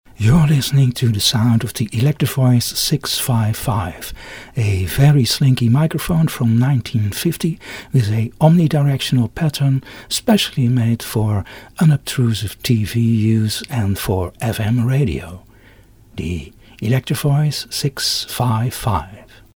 Listen to the sound of the EV 655
Electro-Voice 655 sound UK.mp3